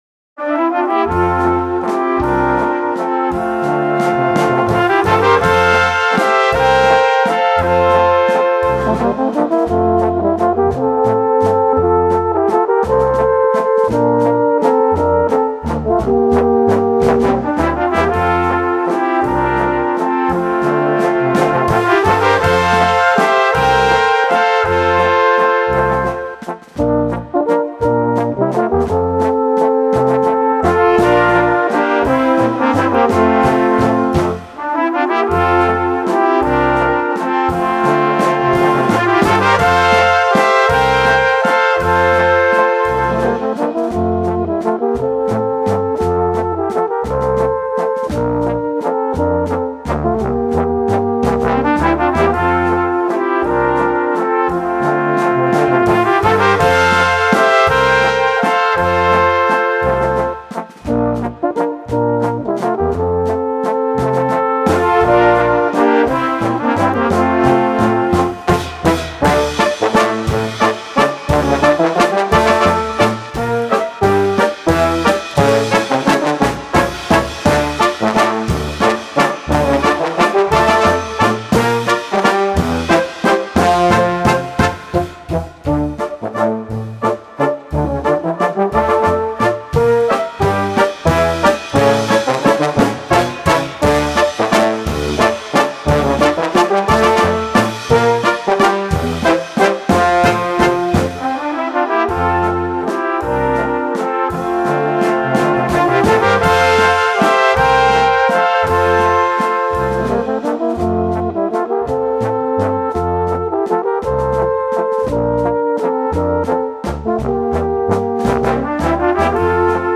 Besetzung: Kleine Blasmusik-Besetzung